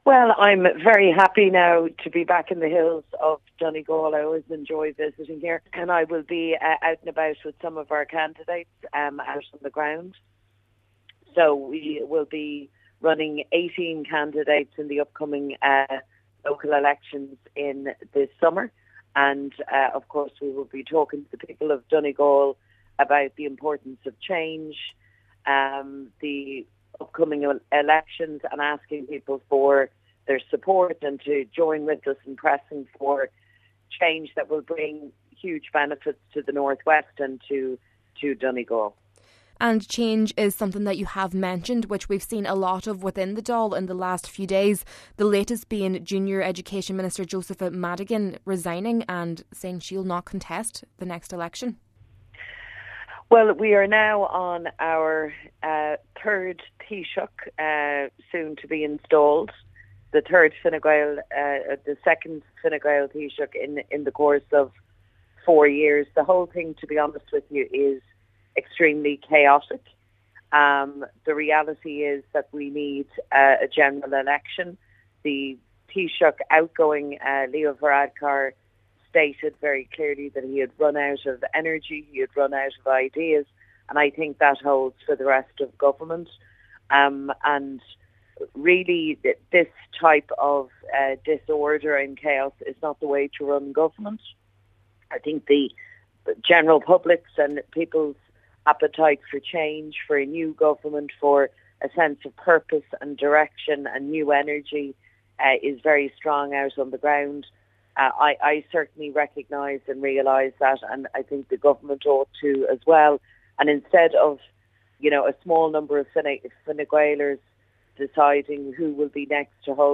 While speaking to Highland Radio News, Deputy MacDonald was asked about last nights revelation that Junior Education Minister Josepha Madigan has resigned and will not contest the next general election.